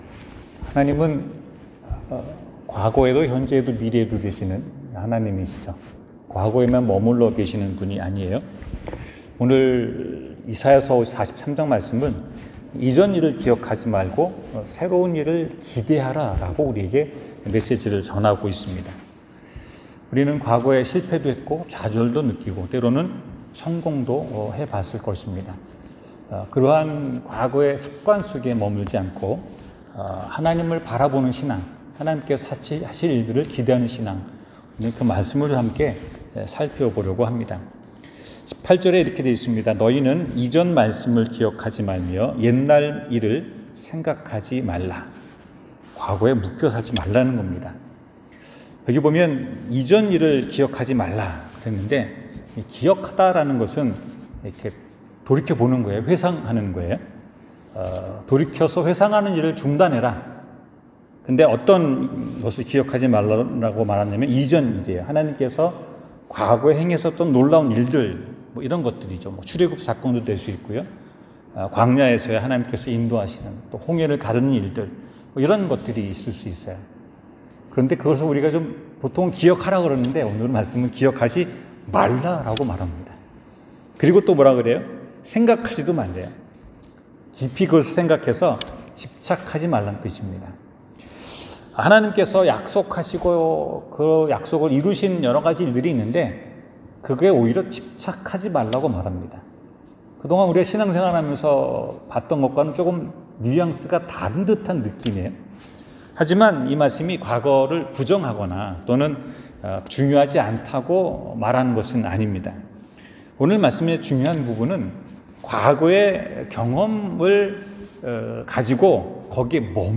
새로운 것을 시작하시는 하나님 성경 : 이사야 43:18-19 설교